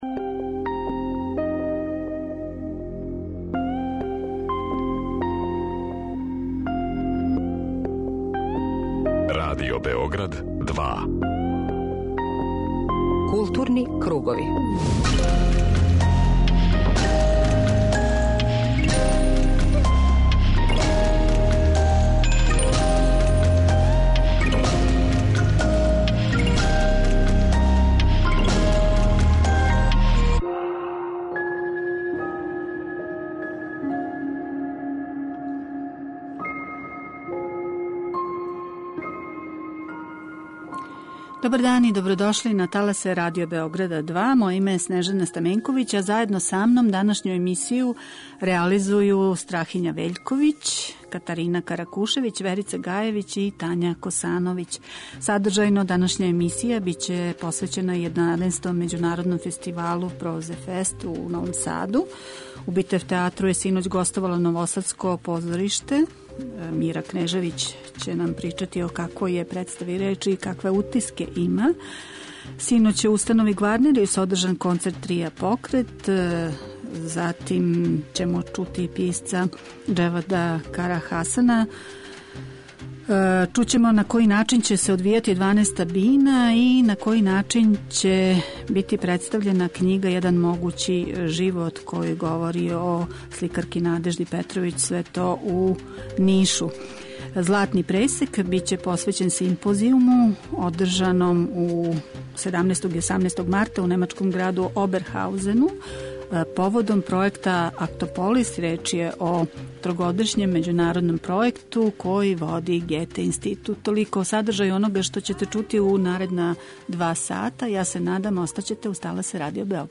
Разговором са њима уједно ћемо најавити и изложбу која ће након Оберхаузена бити представљена и у Београду, у Музеју града Београда у Ресавској.